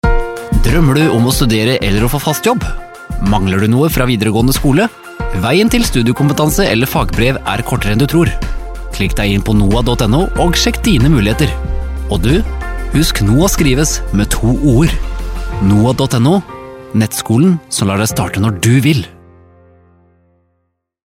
NooAs annonser i Radio Grenland.
Mannsstemmen til annonser i Radio Grenland
NooA-nettskole-2019-Mann-radio.mp3